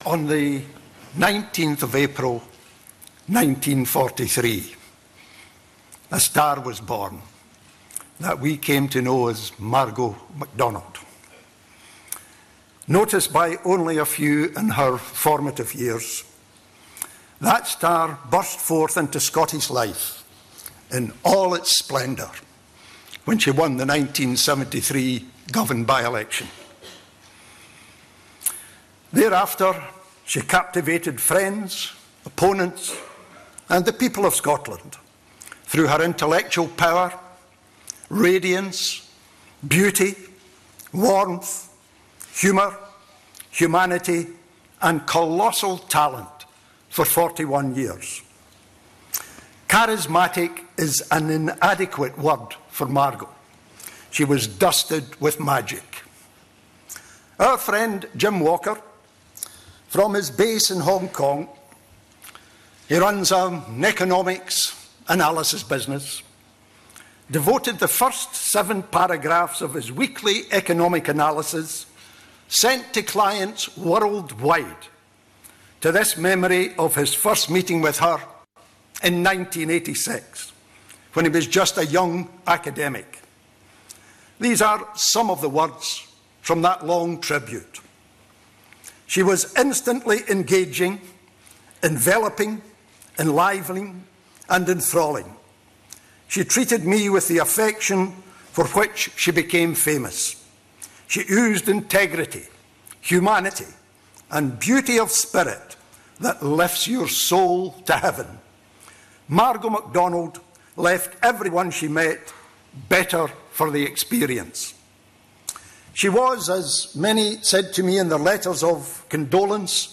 More than 1000 people gathered in Edinburgh city centre to celebrate the life of the late MSP. This is the first part of her husband's memorable speech.